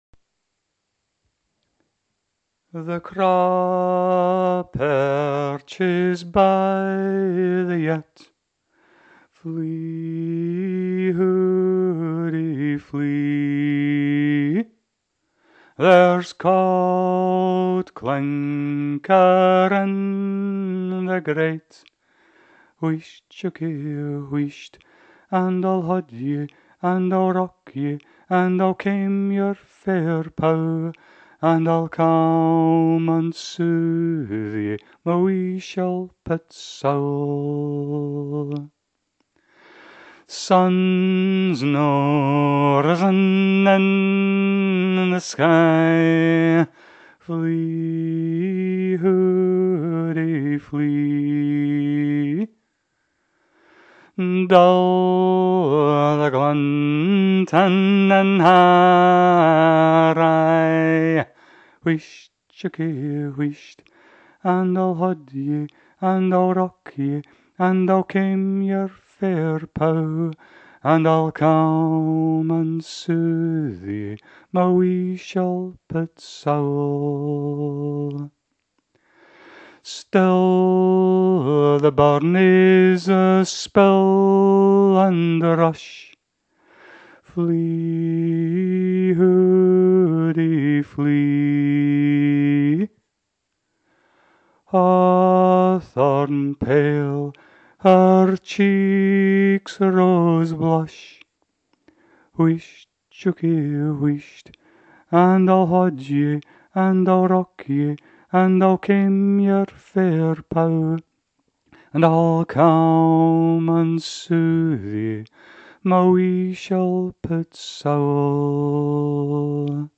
A dirge / lullaby of a song, quite a poetic composition, working with compression of ideas and imagery.
I create for my own voice - for an unaccompanied solo delivery.